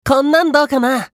少年系ボイス～戦闘ボイス～